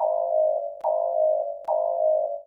attack.ogg